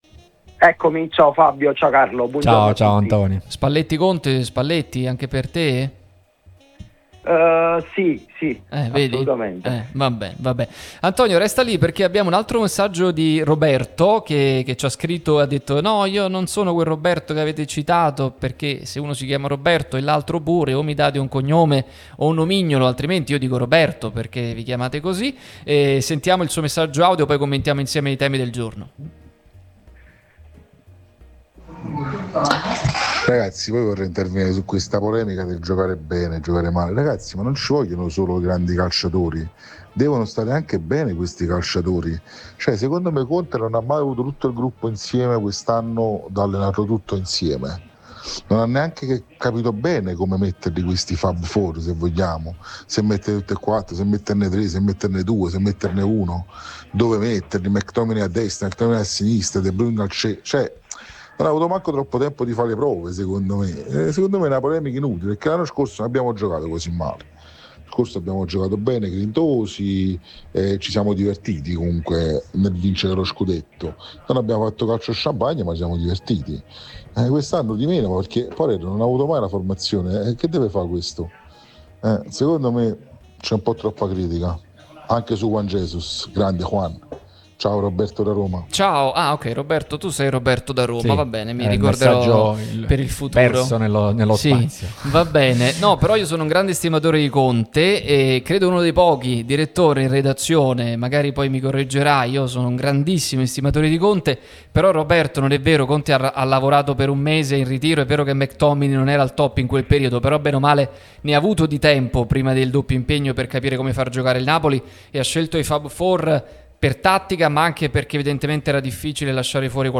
Podcast Il punto del Direttore: “Conte indecifrabile.